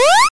8-bit 8bit arcade blip bomb chip chiptune explosion sound effect free sound royalty free Gaming